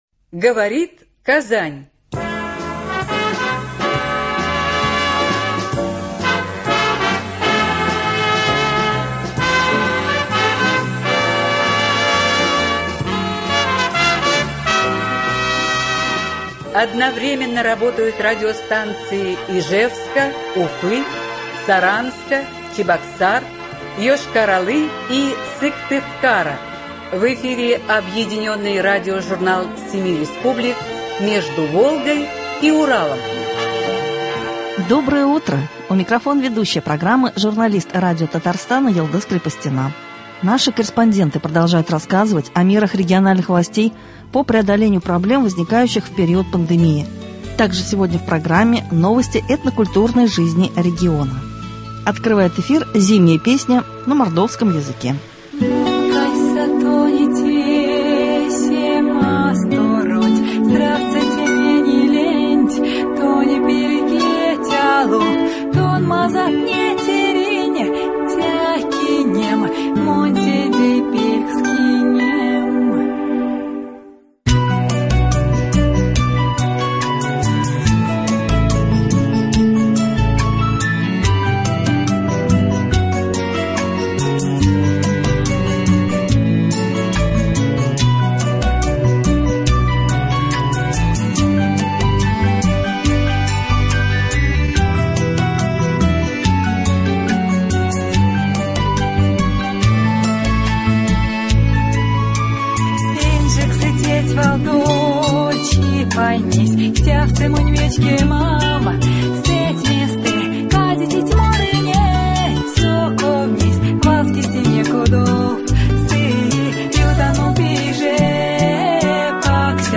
Объединенный радиожурнал семи республик.